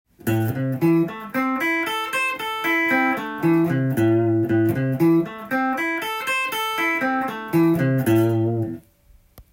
指がなまらないギターフレーズ集TAB譜
譜面通り弾いてみました